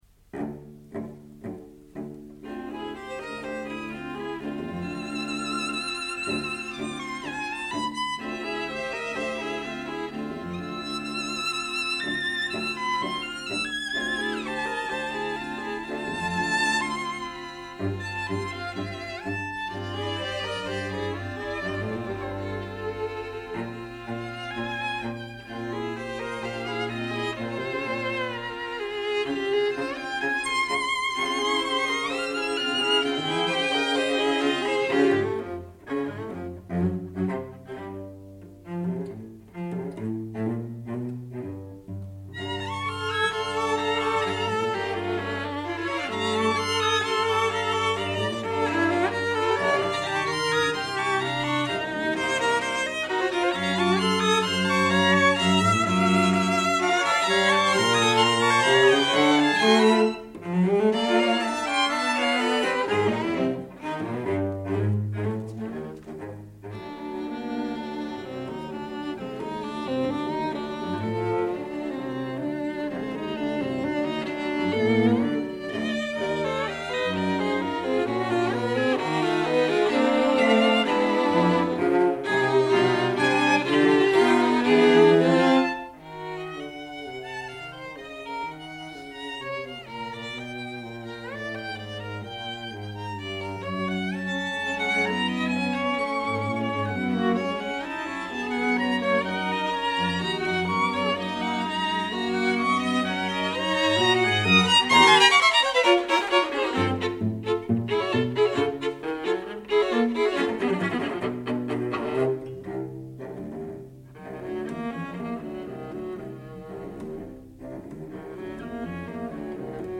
recorded January 13, 1956 - Radiostudio - Zurich Switzerland